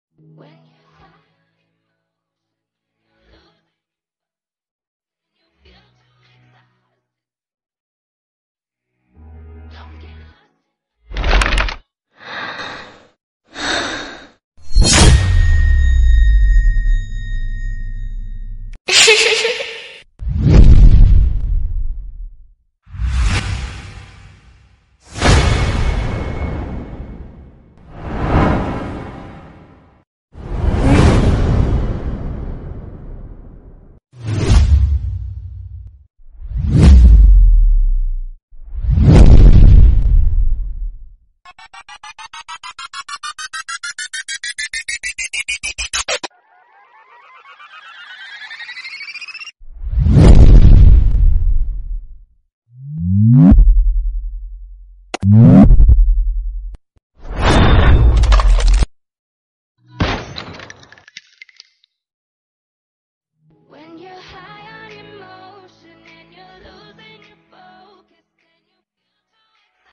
Best Sound Effects For Pubg Sound Effects Free Download